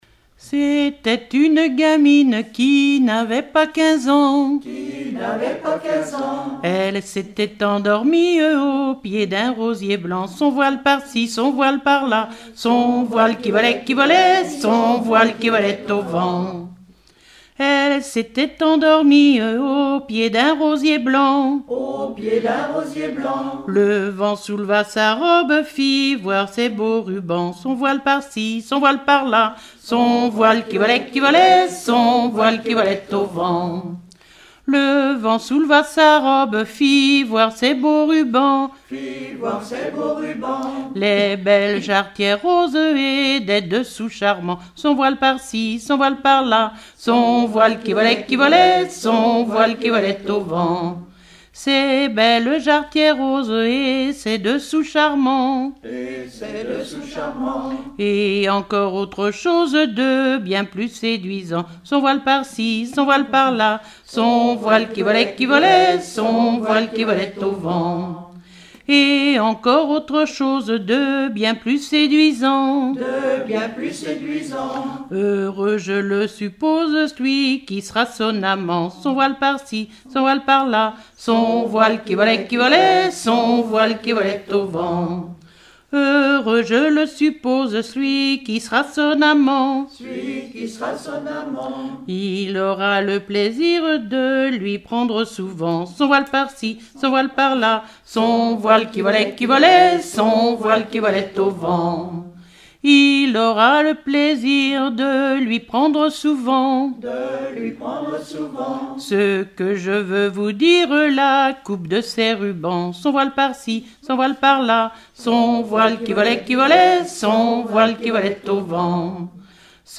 Genre laisse
Collectif-veillée (2ème prise de son)
Pièce musicale inédite